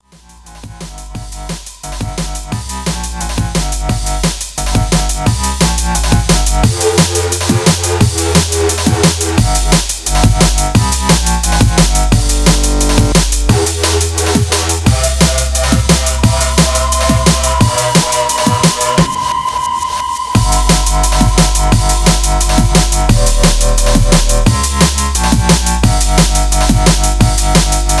Android, Elektronisk musik